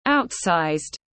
Quá cỡ tiếng anh gọi là outsized, phiên âm tiếng anh đọc là /ˈaʊtsaɪzd/ .
Outsized /ˈaʊtsaɪzd/